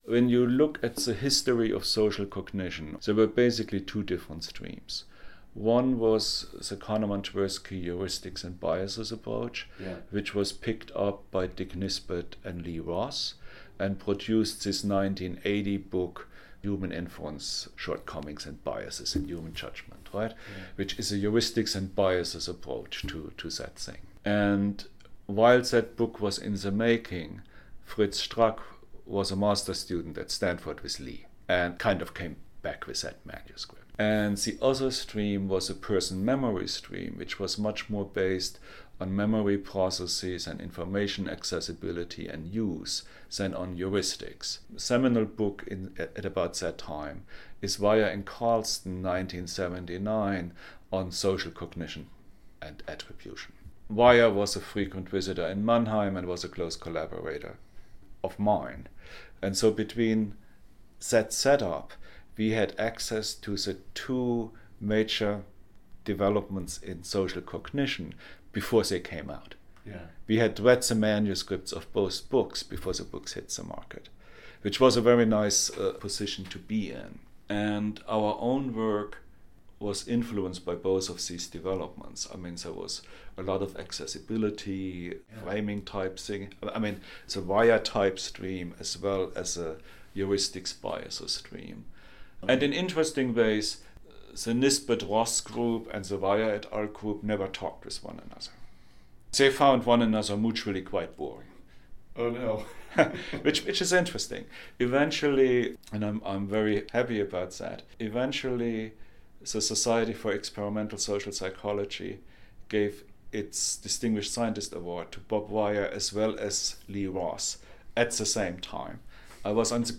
In this excerpt, Dr. Schwartz recalls  some of this history in late 1979 into the early 1980’s at Mannheim (about 2:30):